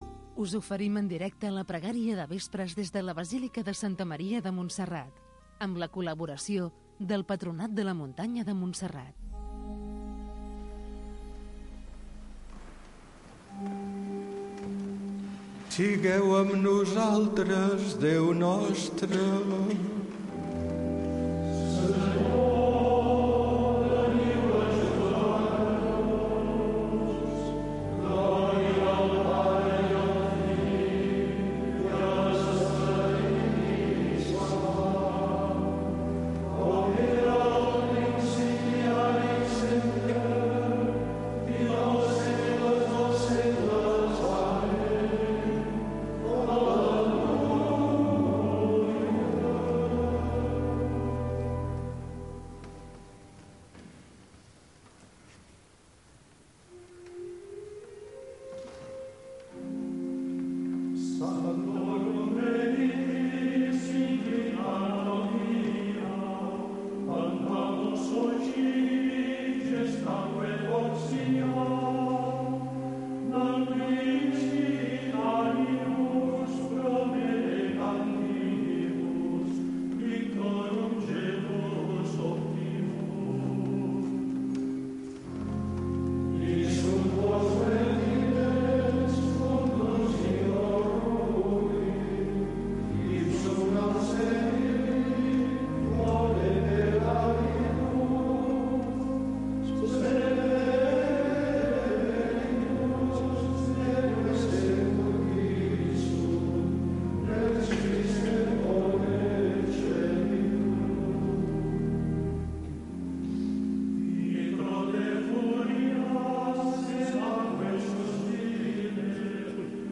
Pregària del vespre per donar gràcies al Senyor. Amb els monjos de Montserrat, cada dia a les 18.45 h